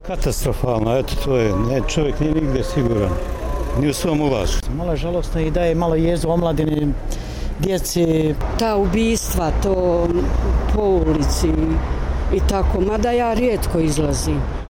Anketa: Osjećaju li se Sarajlije sigurno u svom gradu: